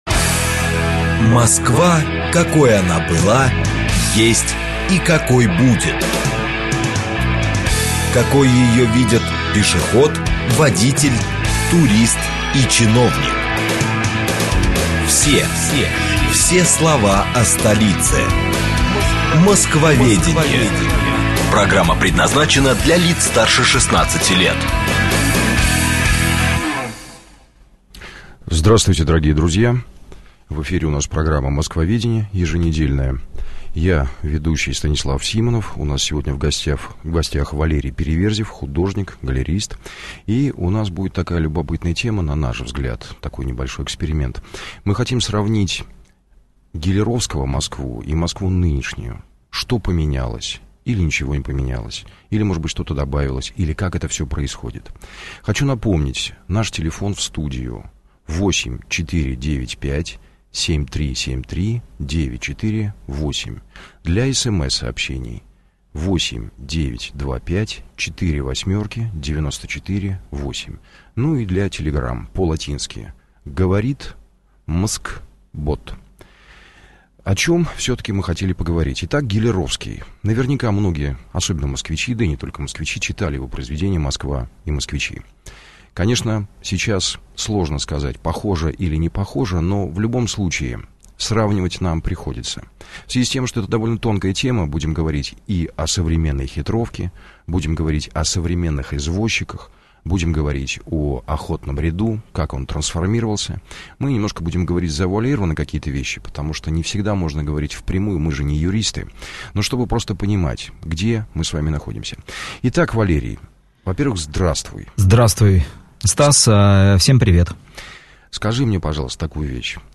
Аудиокнига "Москва и москвичи" Гиляровского | Библиотека аудиокниг